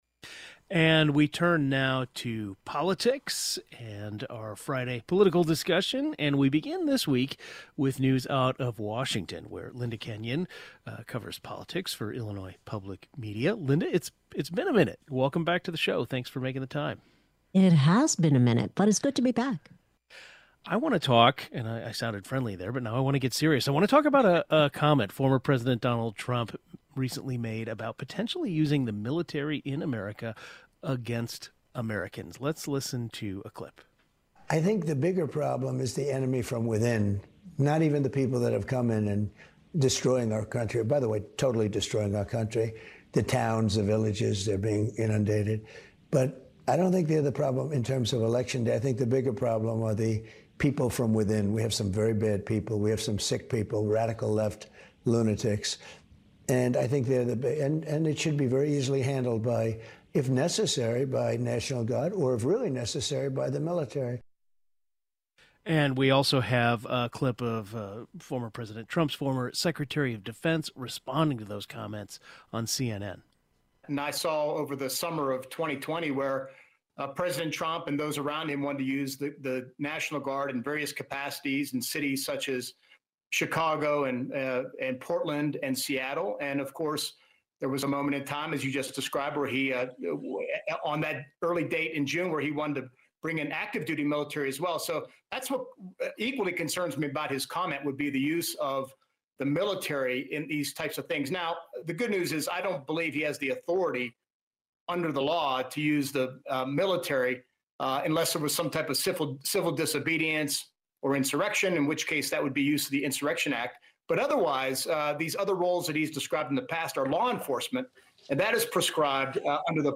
Politics Roundtable: Trump talks about using military against opposition and Illinois voters consider policy changes
Our Friday politics roundtable included comments from former president Donald Trump, who talked about potentially using the military to quiet political opponents.